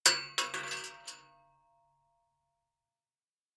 Bullet Shell Sounds
rifle_metal_1.ogg